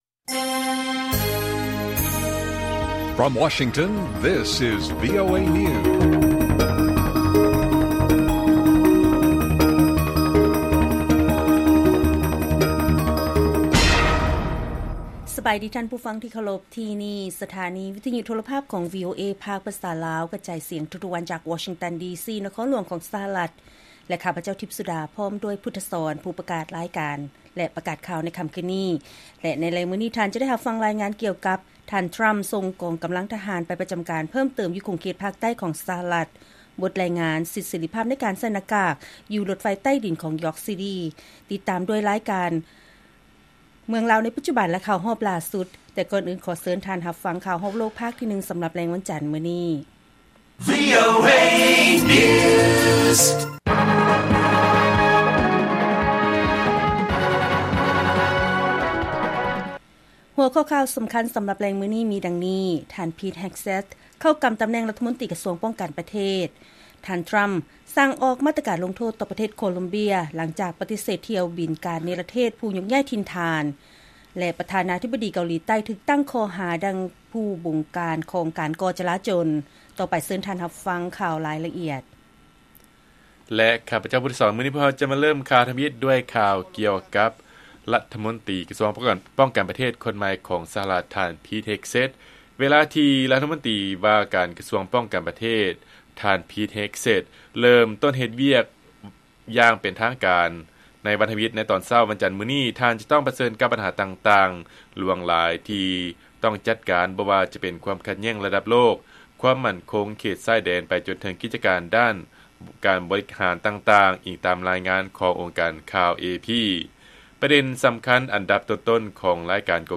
ລາຍການກະຈາຍສຽງຂອງວີໂອເອລາວ: ທ່ານ ພີດ ເຮກເຊັດ ເຂົ້າກຳຕຳແໜ່ງລັດຖະມົນຕີກະຊວງປ້ອງກັນປະເທດ